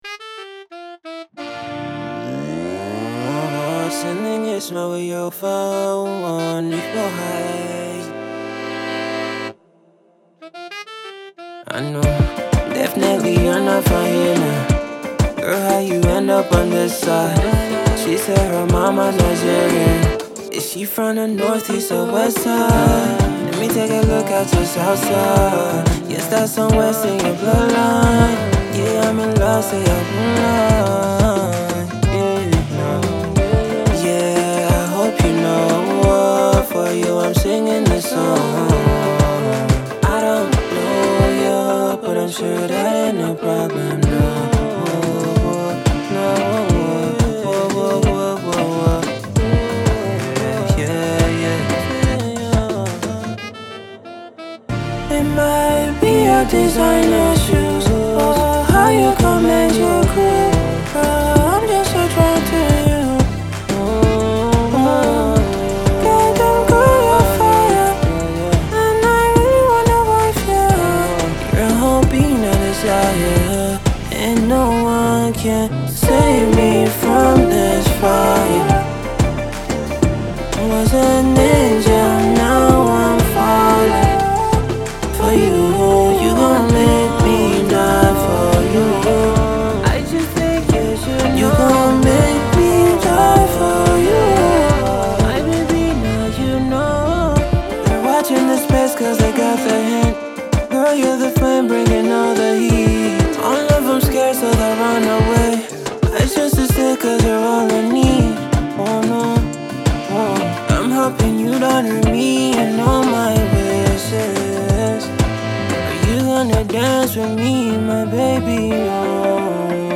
Afro (Pop)/R&B